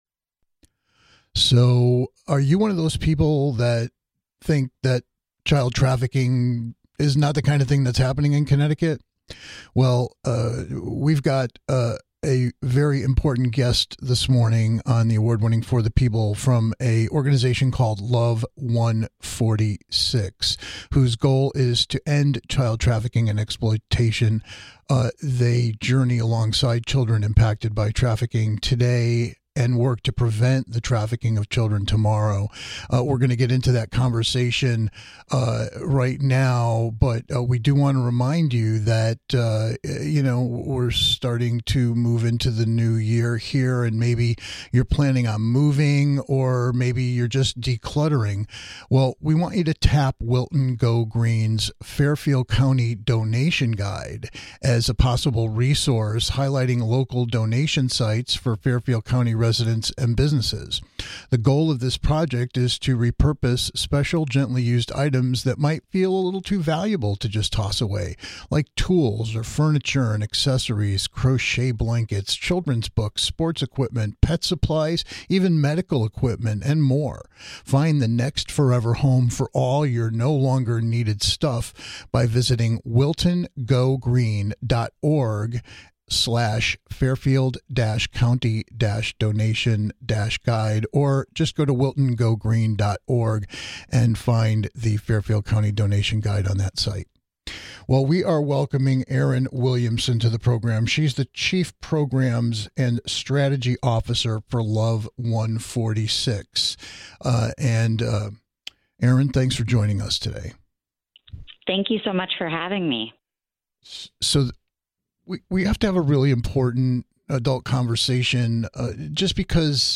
If you care about kids, you can't miss this eye-opening conversation with LOVE146 that will help frame what we're facing here in Connecticut when it comes to child trafficking, and what you can do to prevent it and help these exploited children have a better life.